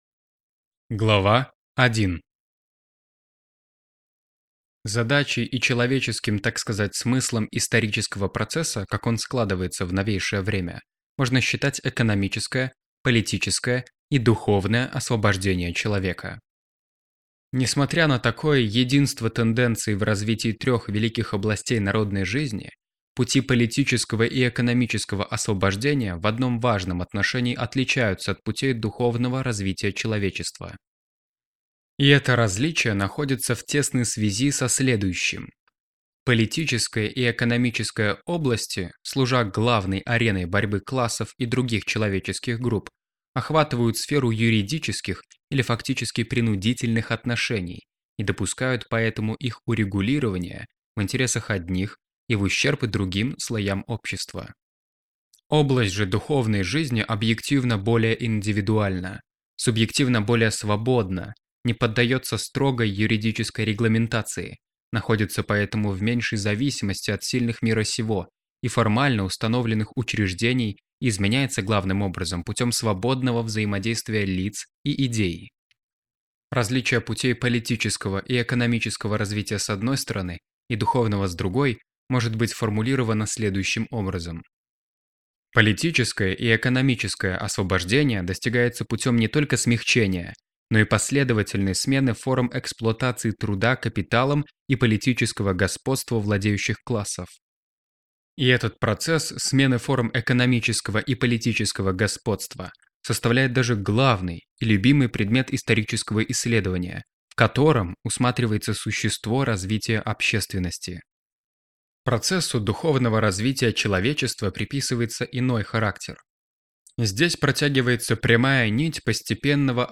Аудиокнига Захват печати капиталом и его последствия | Библиотека аудиокниг